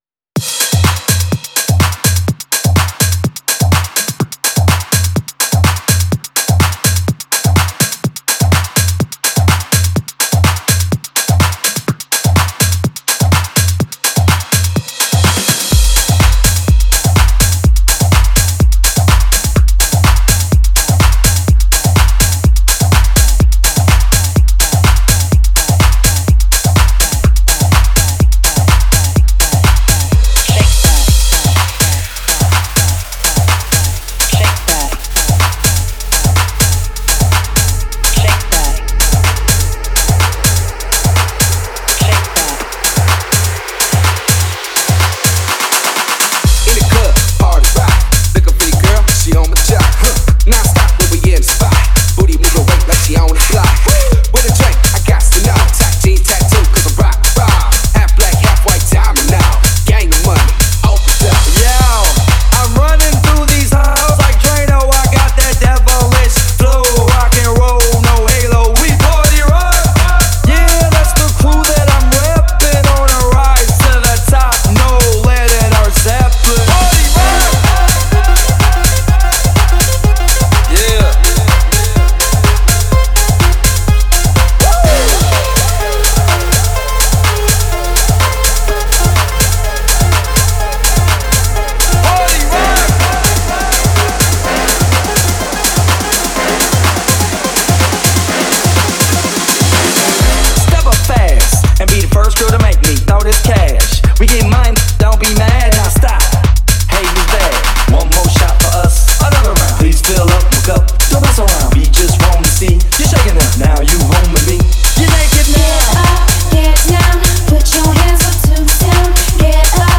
Novo remix
em uma pegada Tech House com bastante energia